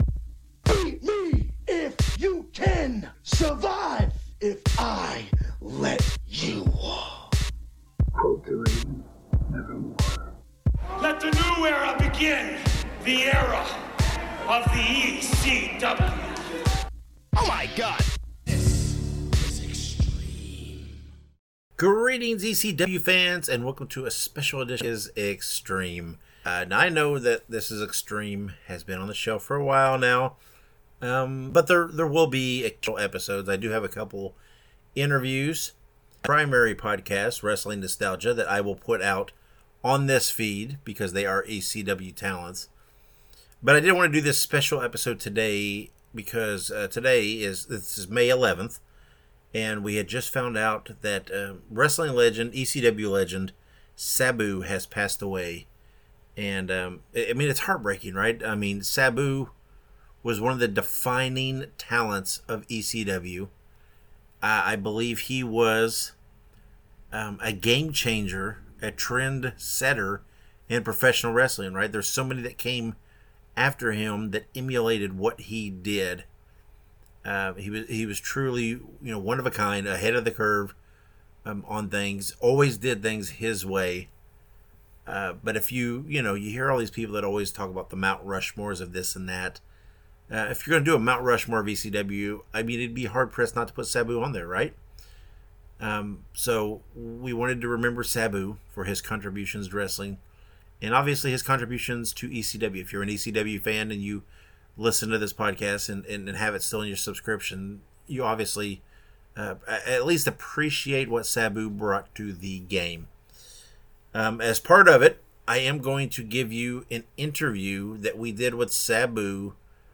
Audio samples from ECW videos.